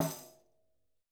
TC2 Perc14.wav